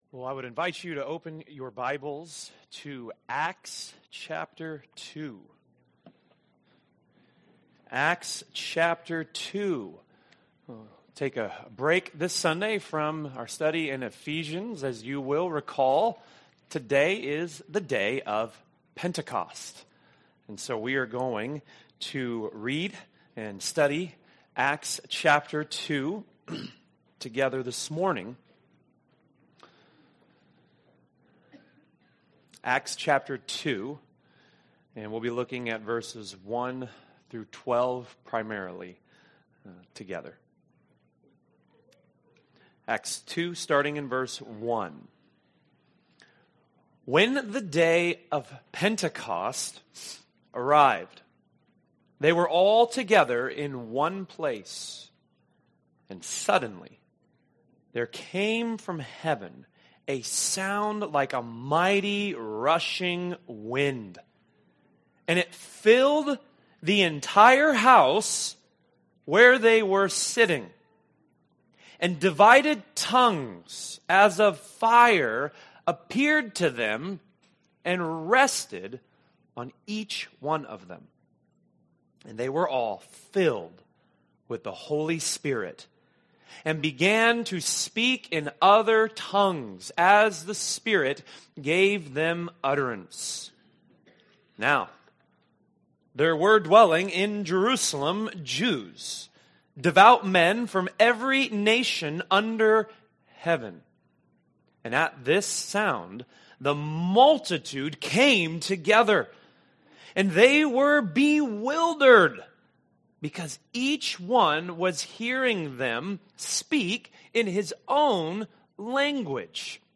Pentecost Sunday sermon 2024